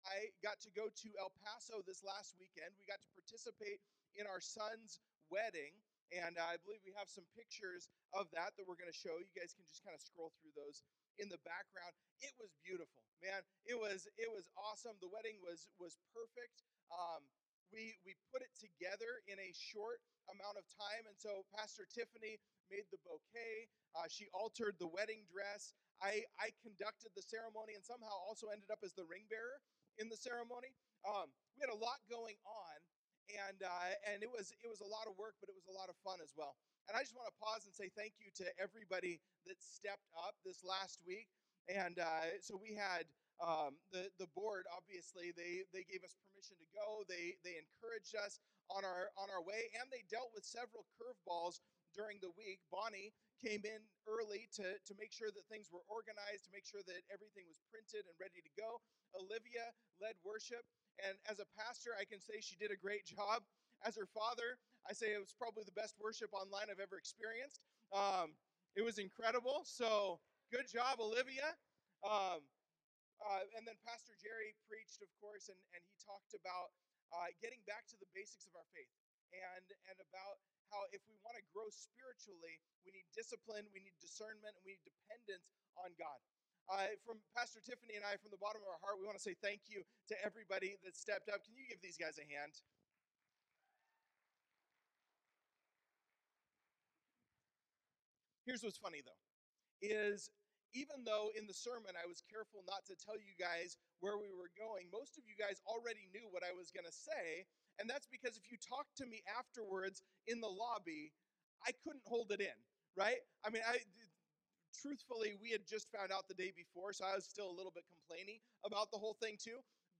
3-22-Sermon-Mp3.mp3